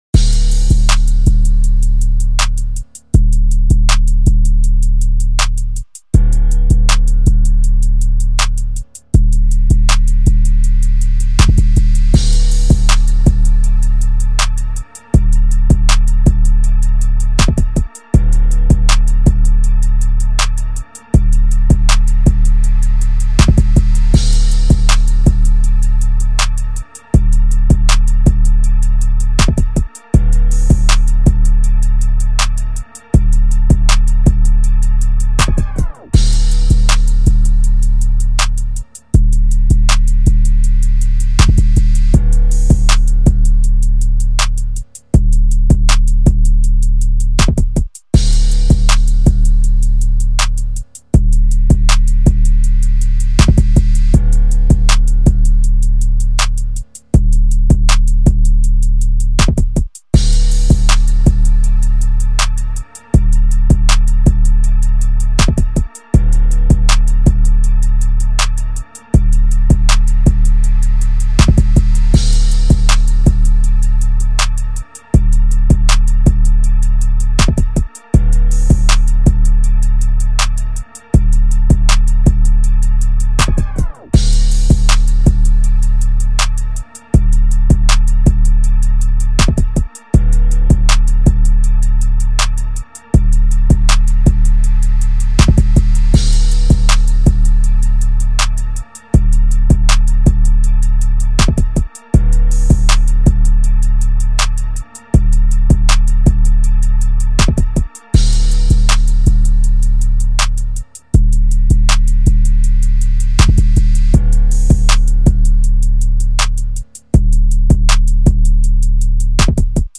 Rap Instrumentals